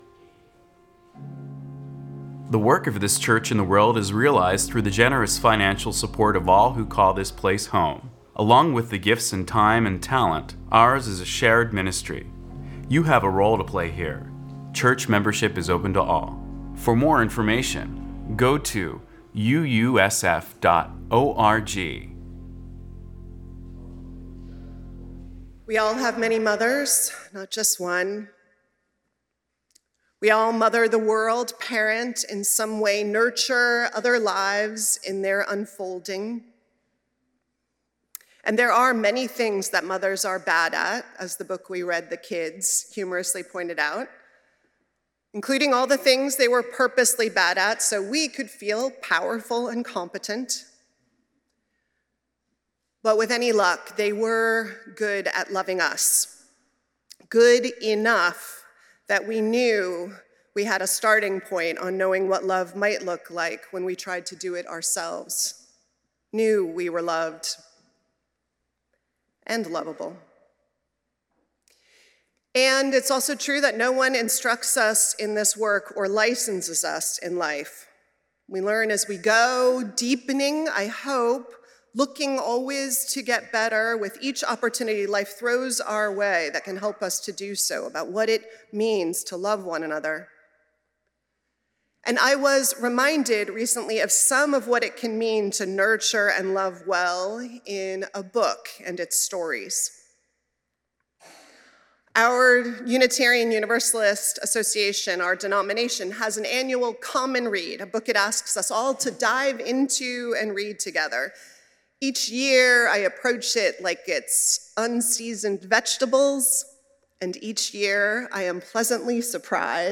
Sermons-First Unitarian Universalist Society of San Francisco « » Holding Open Possibilities for Each Other